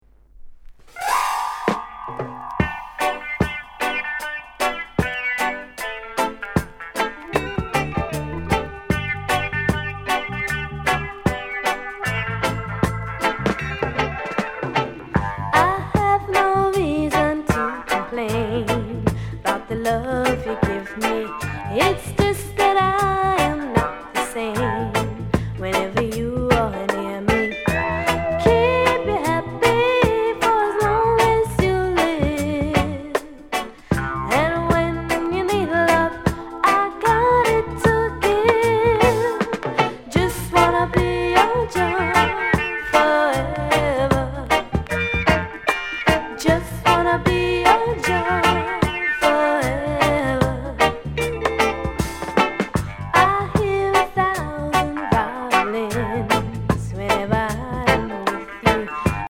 NICE SOUL COVER